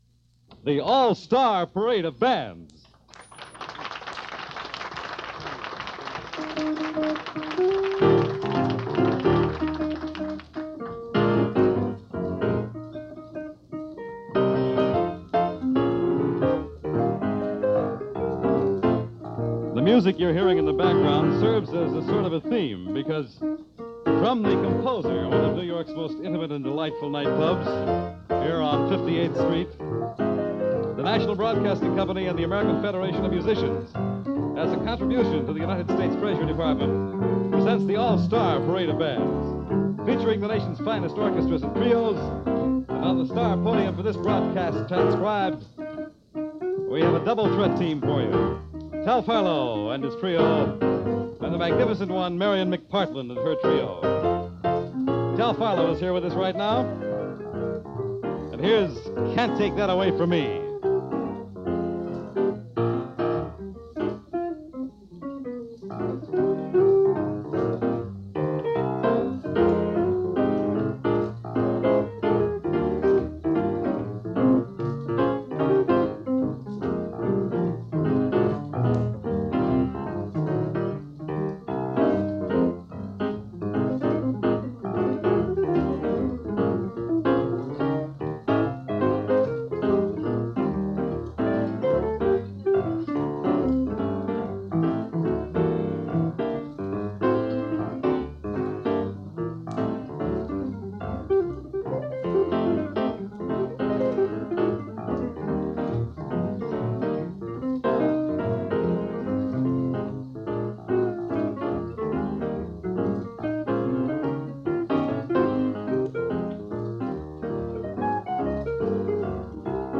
cool and swinging.